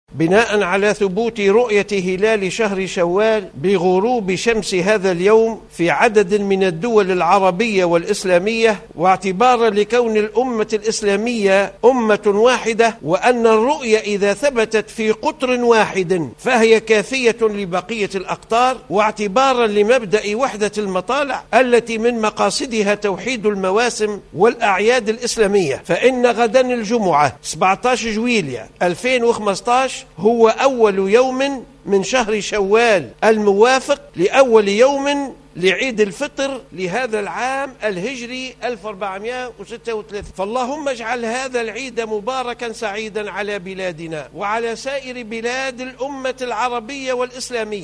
كلمة مفتي الجمهورية